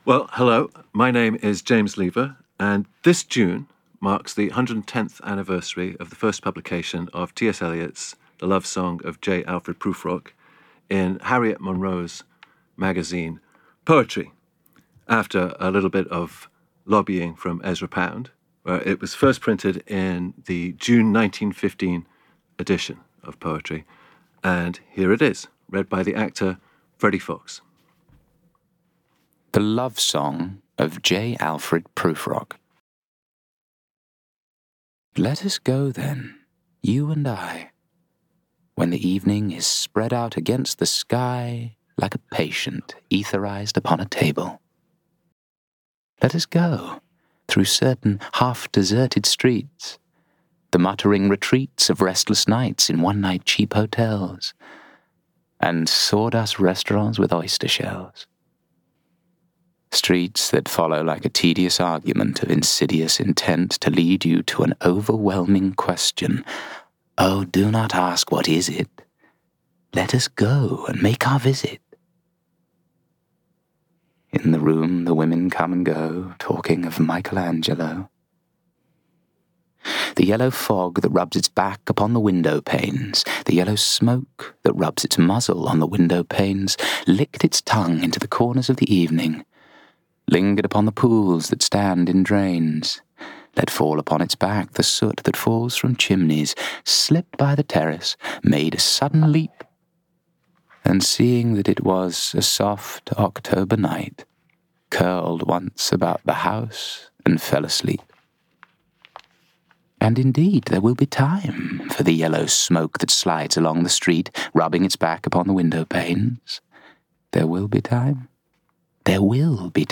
He also reads two other early poems of Eliot’s which have a bearing on Prufrock, and two poems by the young Eliot’s greatest poetical influence, Jules Laforgue.